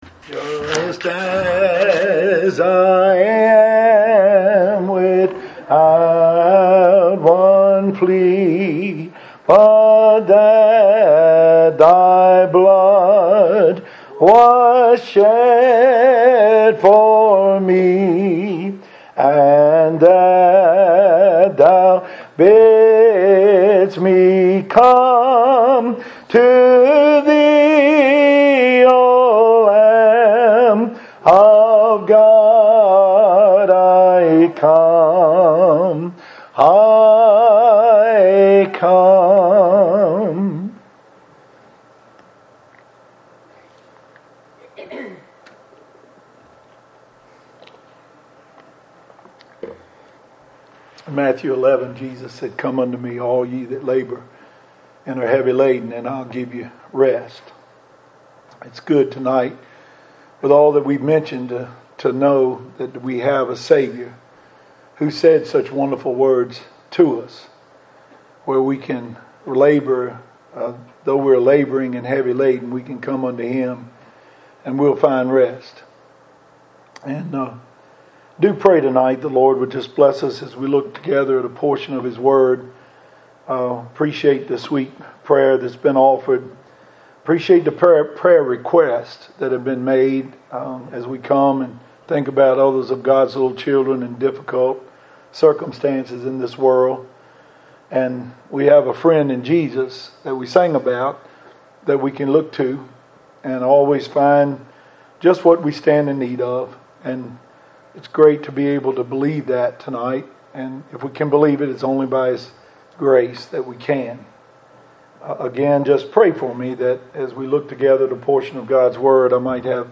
Old School Primitive Baptists Audio Sermons